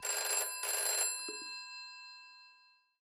phone_ringing.wav